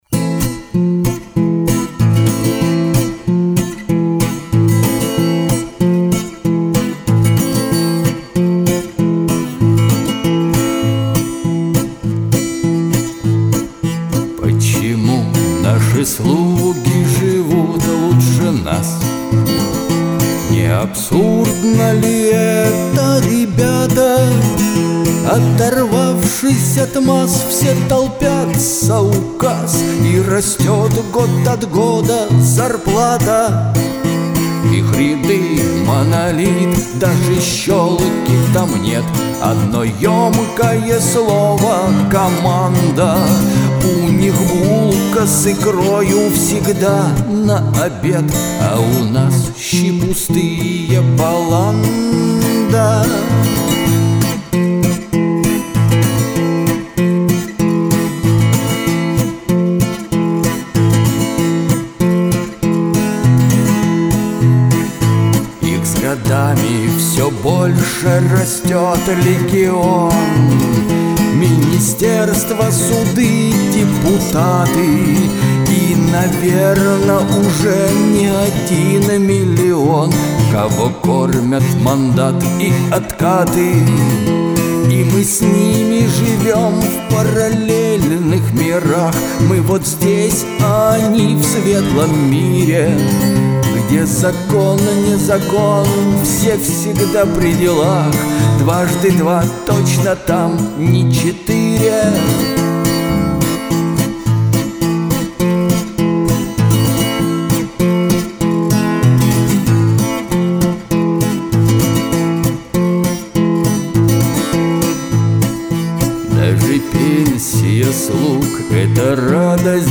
Песня "Слуги народа", ст.